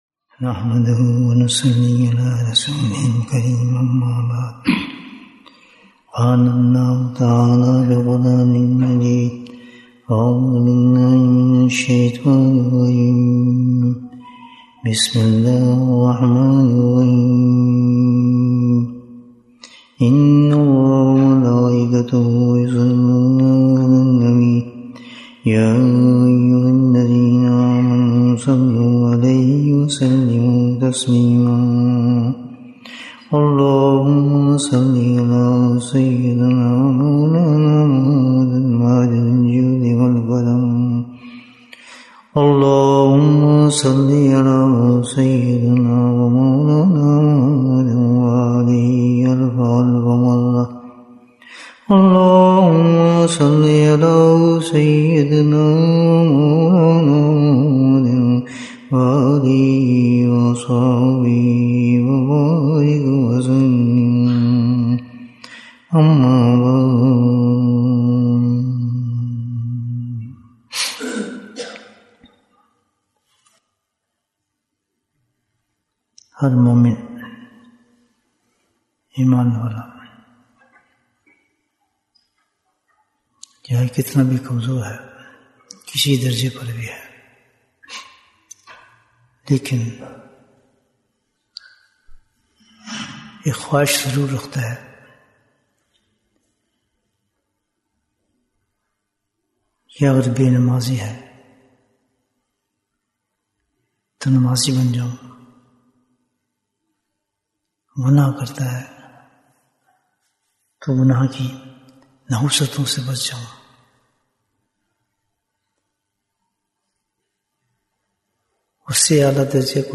Bayan, 82 minutes31st October, 2024